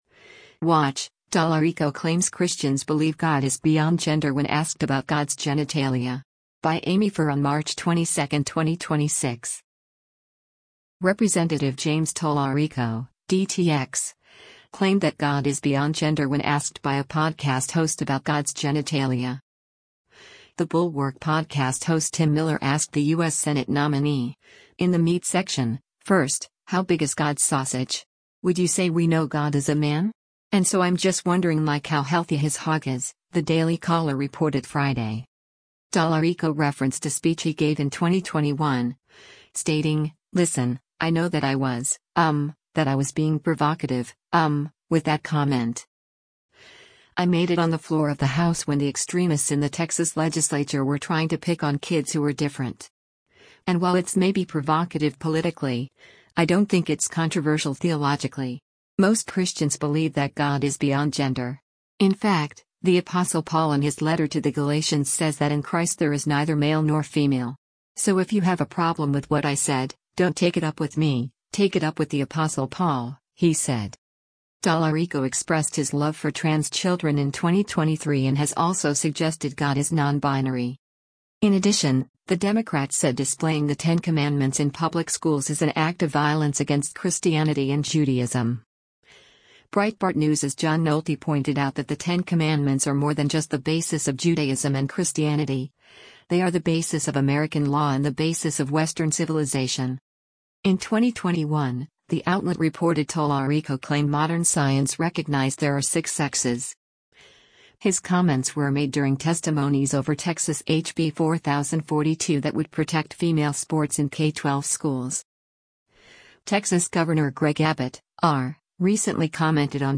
Rep. James Talarico (D-TX) claimed that God is “beyond gender” when asked by a podcast host about God’s genitalia.